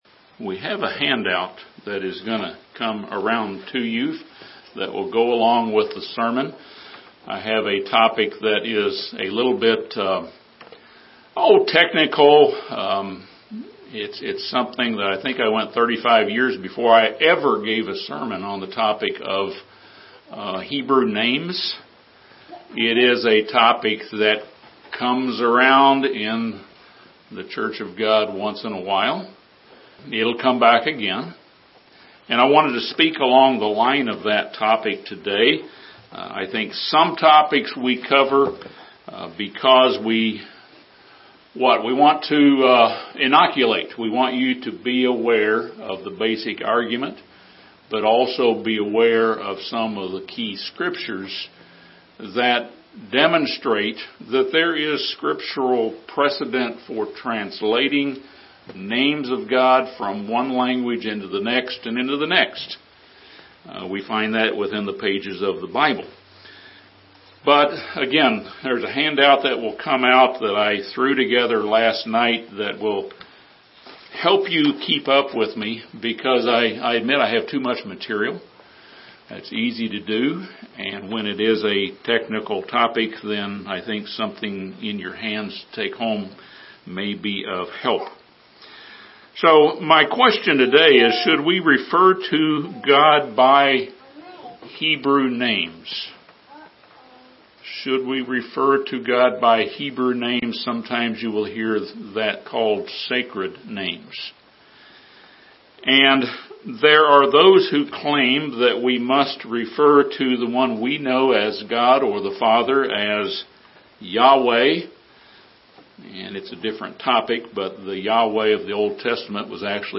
The New Testament abundantly quotes from the Old Testament and translates Hebrew names for God into Greek words. This sermon discusses many scriptural precedents of translating God’s names from one language into another.